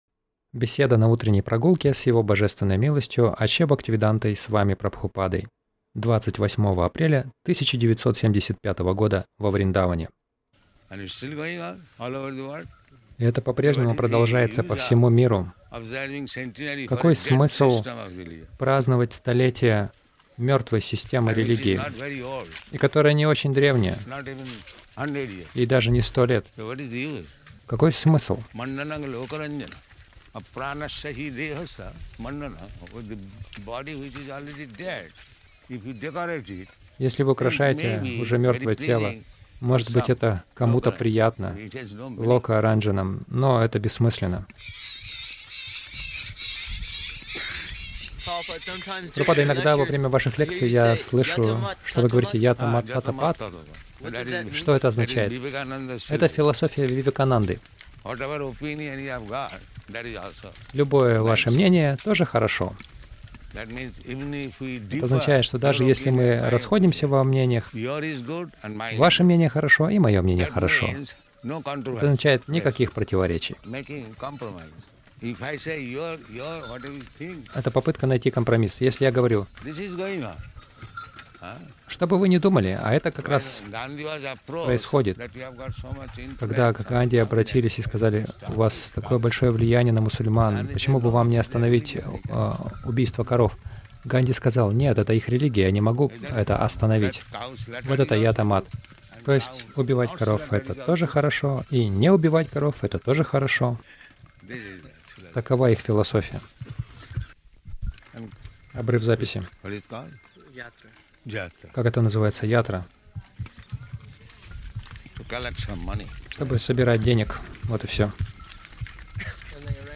Милость Прабхупады Аудиолекции и книги 28.04.1975 Утренние Прогулки | Вриндаван Утренние прогулки — Господь Чайтанья — Парам Брахмасми Загрузка...